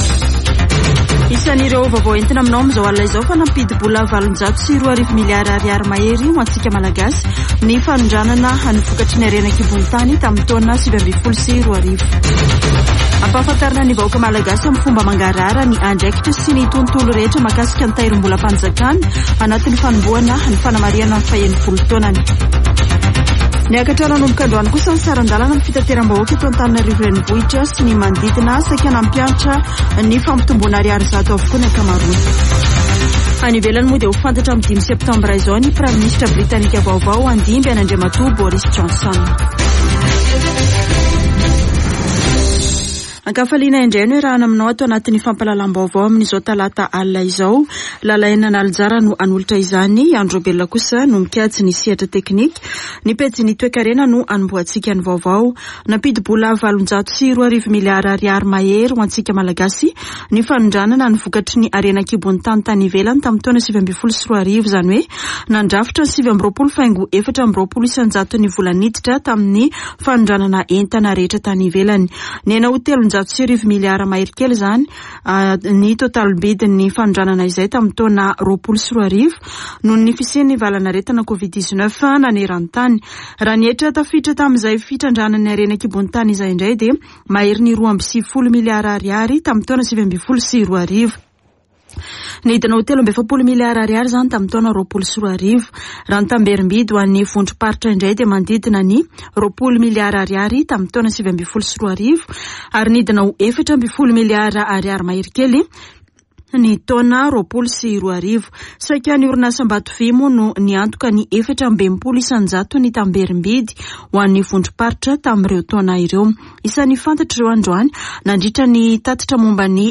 [Vaovao hariva] Talata 12 jolay 2022